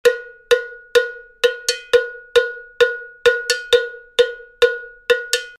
LP Rock Ridge Rider Cowbell
This patented bell is based on the Rock Cowbell and features a red Jenigor plastic bar across the top edge secured with specially designed rivets. The Jenigor bar has a dampening effect on the sound and resists the denting that inevitably occurs when struck with a drumstick.